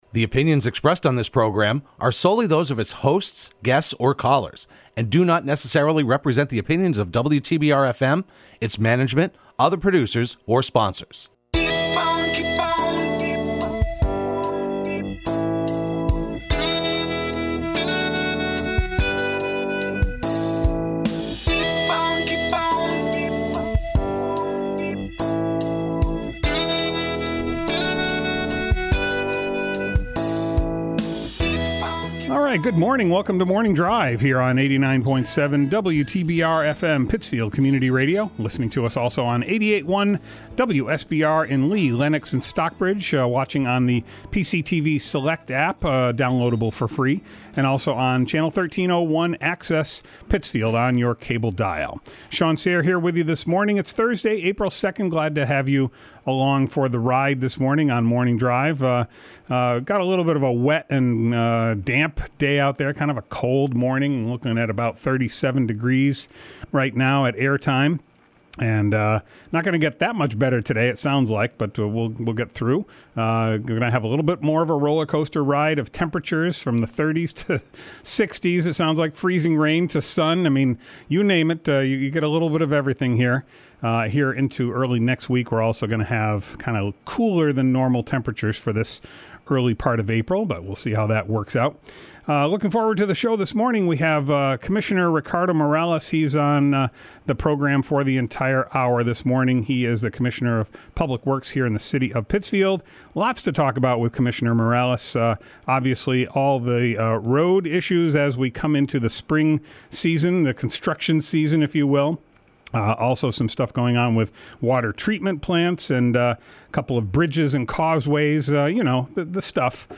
Pittsfield Commissioner of Public Works Ricardo Morales in the studio for the full hour.